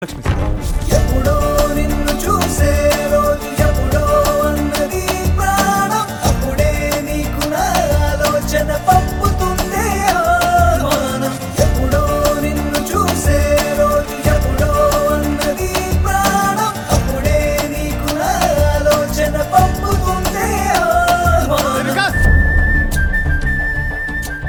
best flute ringtone download | love song ringtone
romantic ringtone download